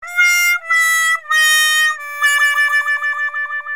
• Качество: 129, Stereo
грусть
звук разочарования
Звук разочарования на твой мобильный телефон